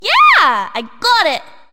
One of Princess Daisy's voice clips in Mario Party 6